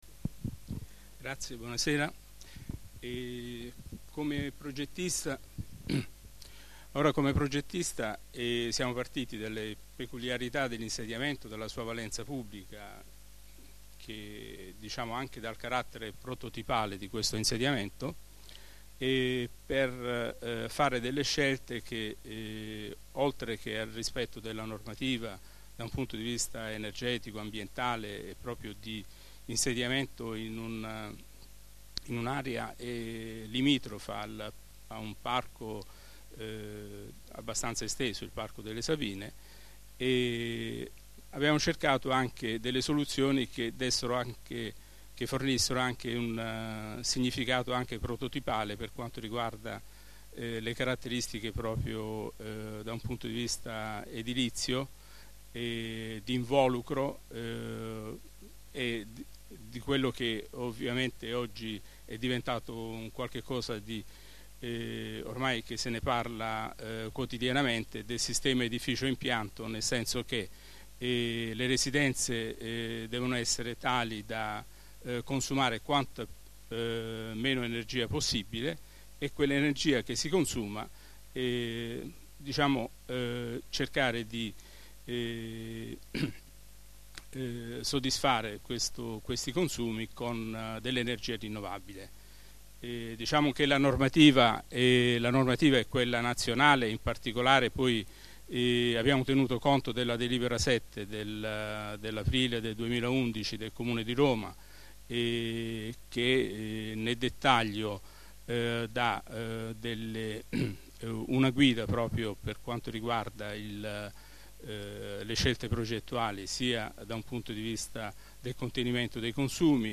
Assemblea Partecipativa BufalottaRegistrazione integrale dell'incontro svoltosi il 22 novembre 2012 presso la Sala Gonzaga del Comando di Polizia Municipale in Via della Consolazione, 4.